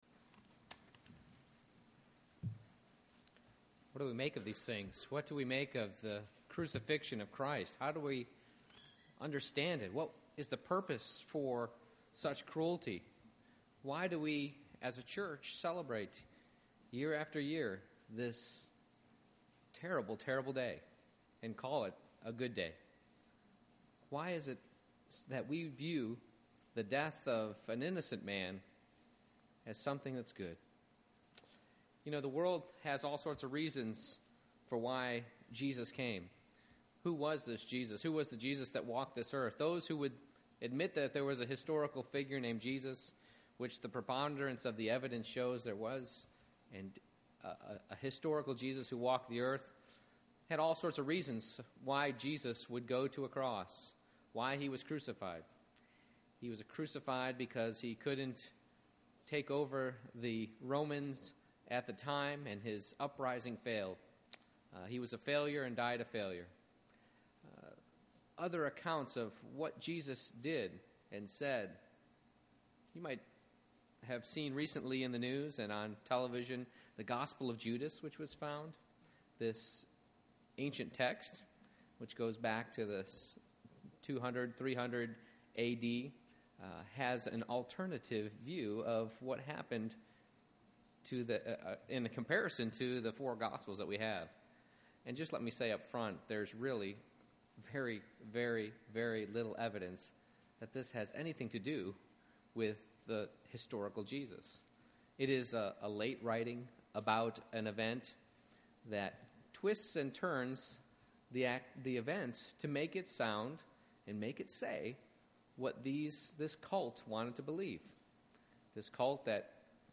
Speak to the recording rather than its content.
Romans 5:12-17 Service Type: Good Friday What do we make of the crucifixion of Christ?